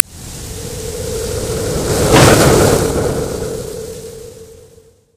gravi_blowout.ogg